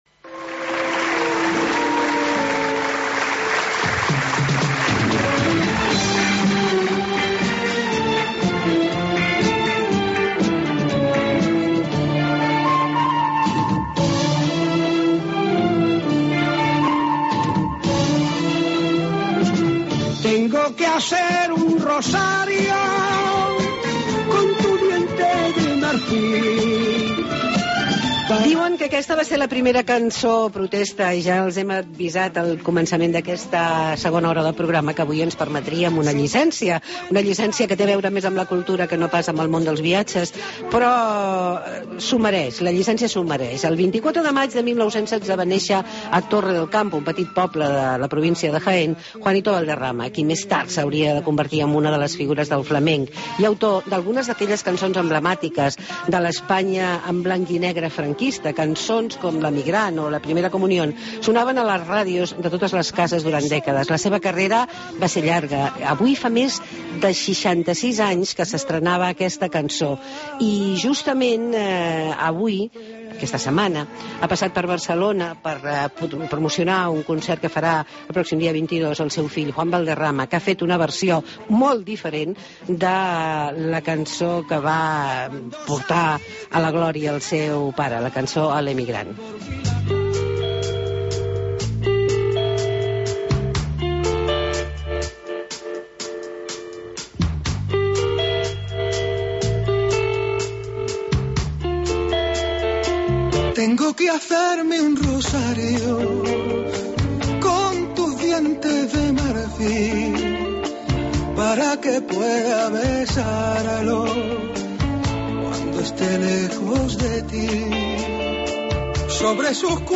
Entrevista con Juan Valderama hijo